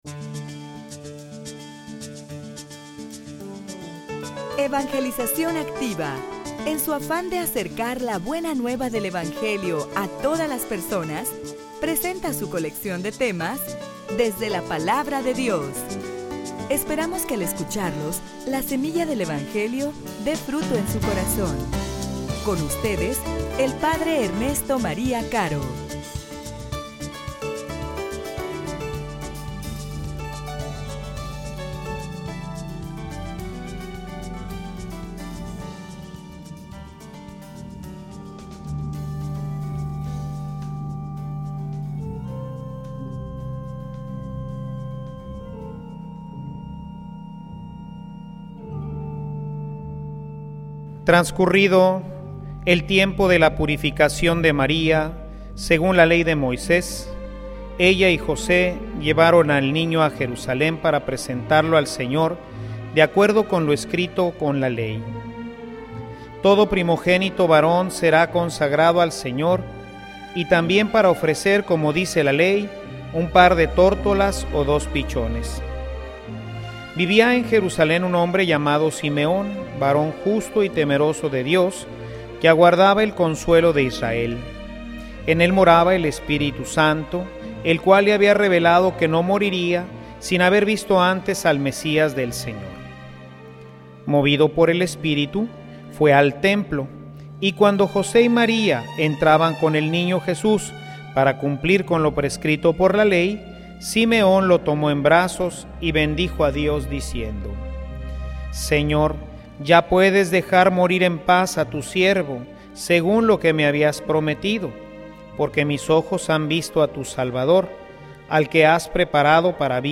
homilia_Dichoso_el_hombre_que_ama_y_obedece_a_Dios.mp3